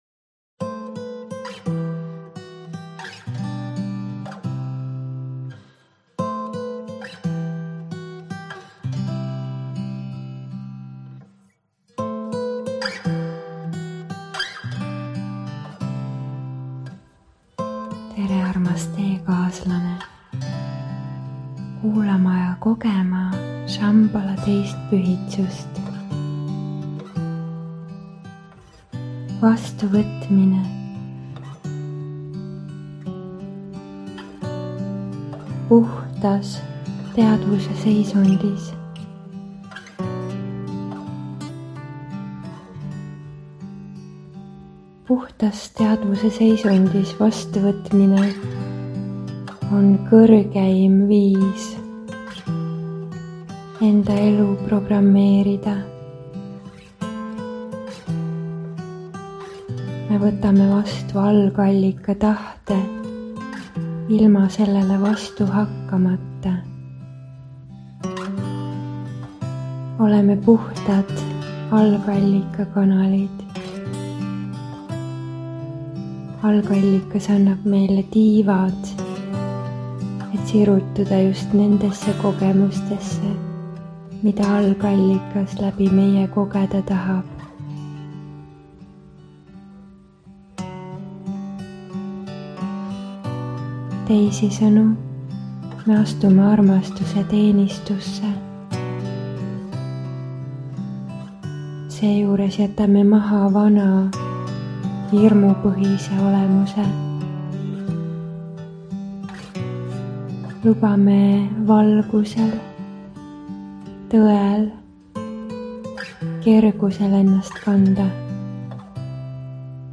MEDITATSIOON